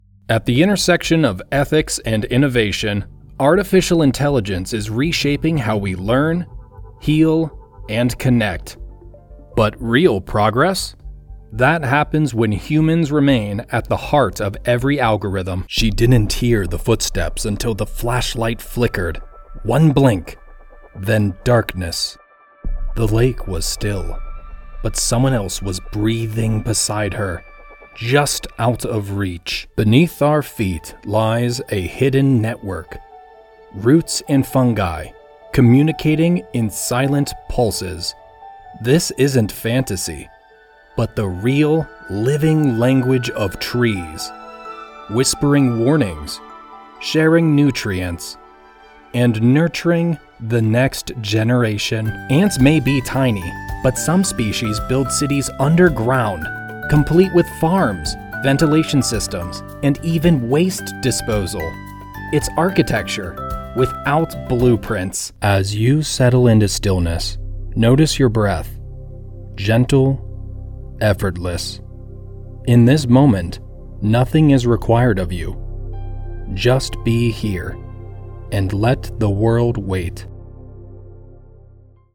Male
My voice is grounded, clear, and medium to medium-low in tone, ideal for authentic, conversational reads.
Audiobooks
Warm, Clear, Confident Narration